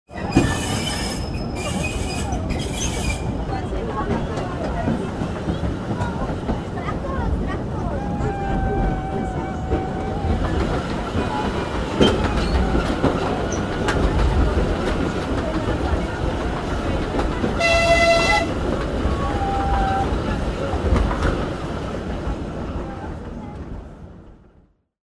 Звуки парка аттракционов
Шум поезда в парке аттракционов